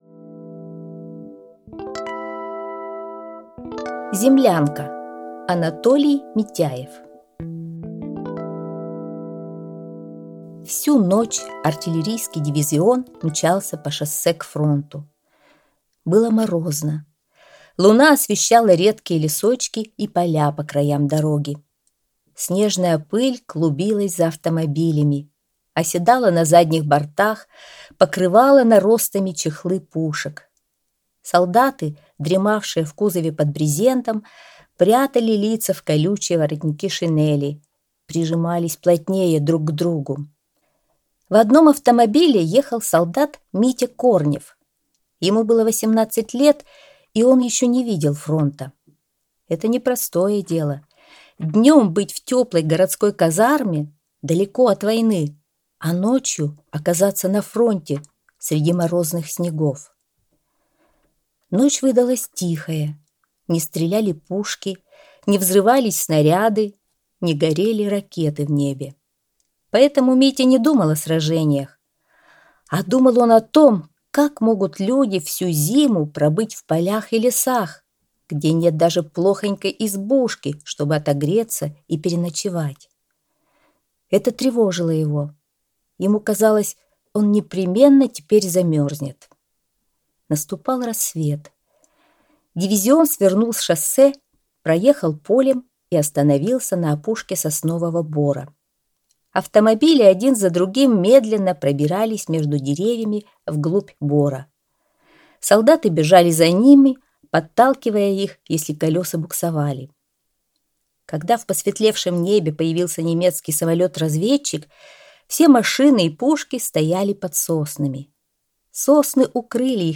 Аудиорассказ «Землянка»